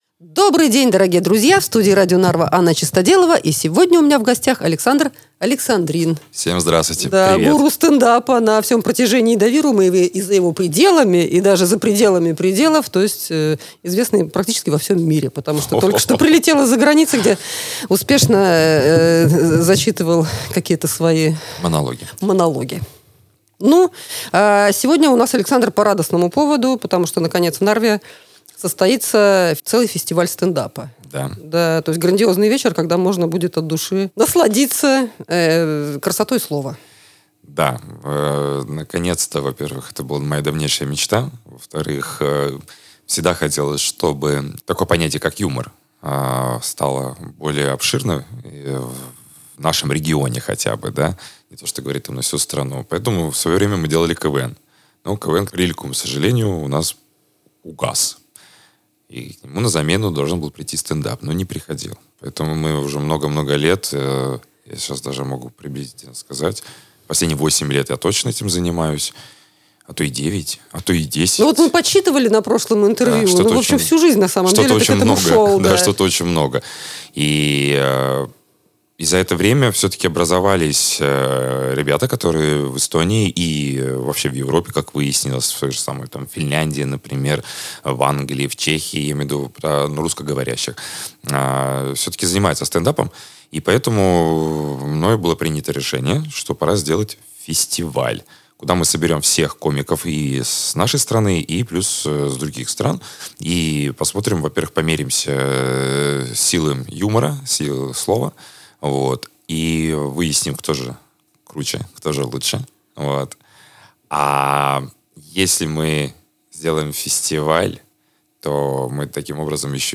Подробности — в интервью.